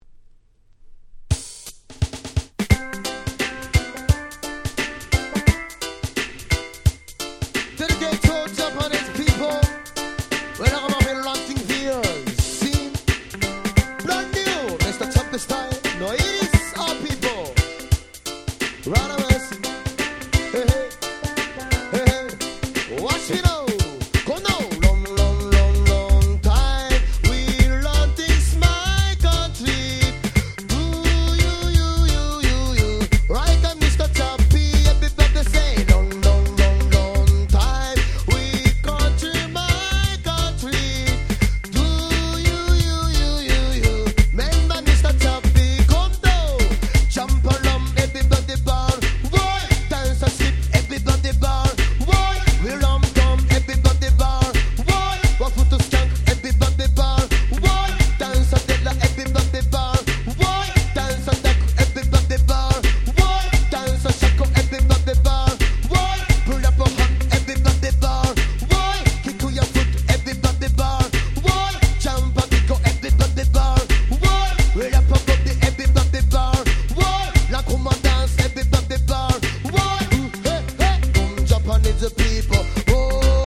90' Japanese Hip Hop Nice Compilation !!